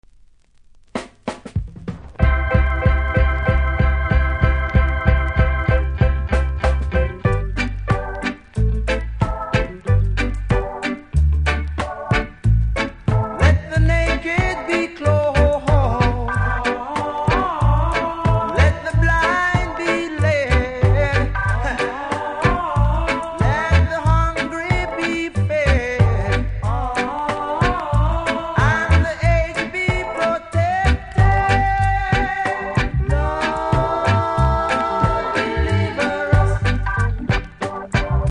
REGGAE 70'S
多少キズありますが音にはそれほど影響しておりませんので試聴で確認下さい。